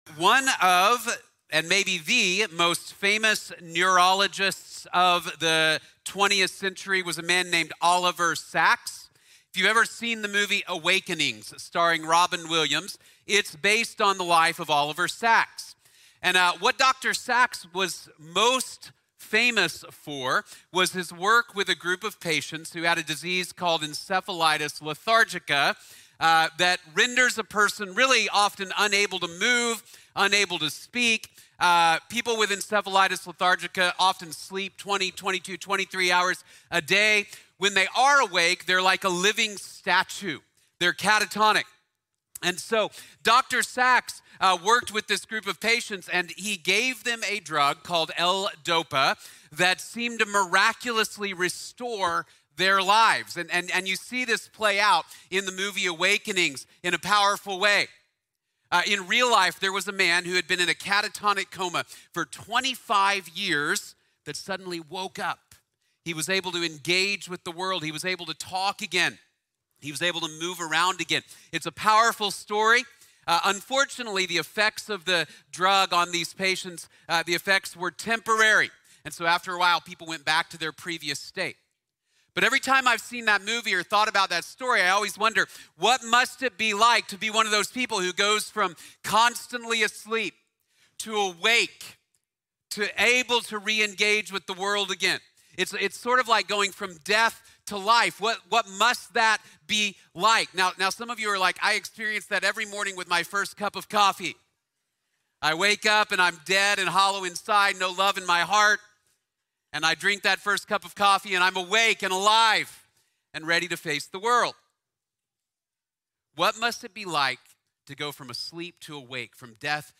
The Greatest Awakening | Sermon | Grace Bible Church